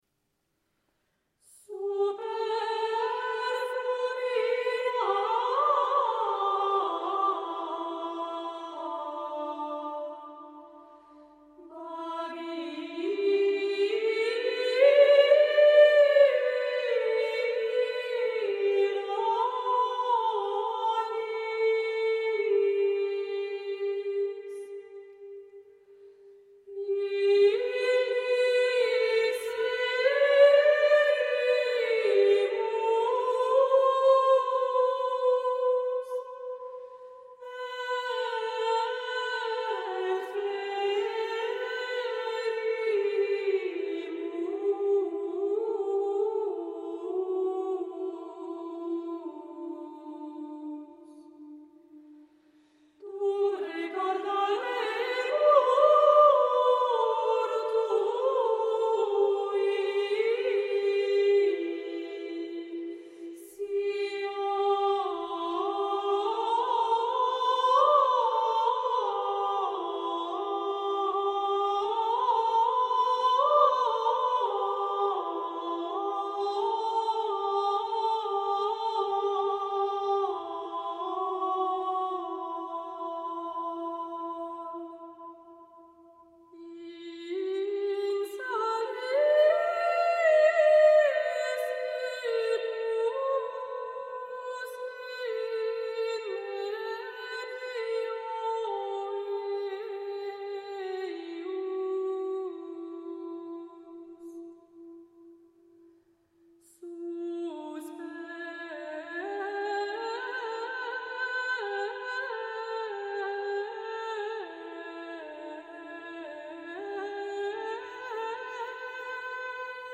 Super flumina Babylonis - offertoire du 20e dim. apr�s la Pentec�te par l'ens. Discantus.